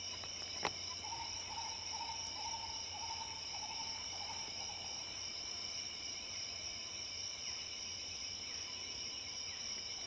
Tapicurú (Mesembrinibis cayennensis)
Nombre en inglés: Green Ibis
Fase de la vida: Adulto
Localidad o área protegida: Reserva Privada y Ecolodge Surucuá
Condición: Silvestre
Certeza: Vocalización Grabada